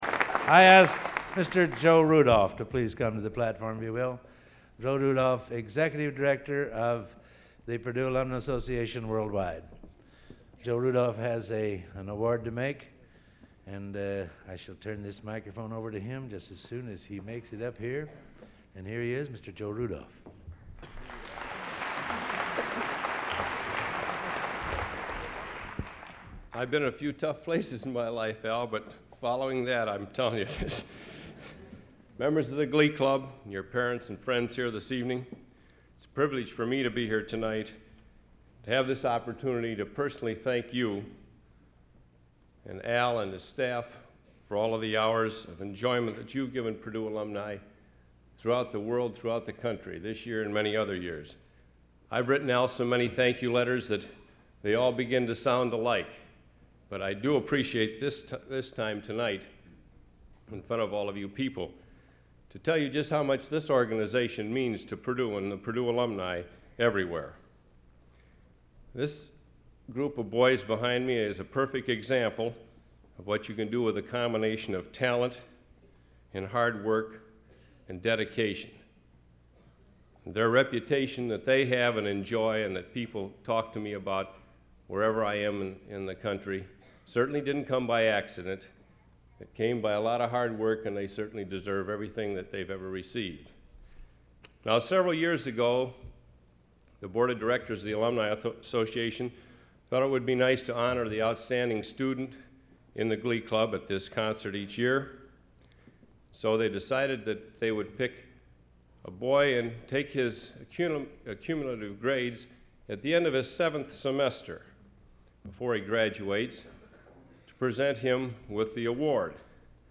Type: Director intros, emceeing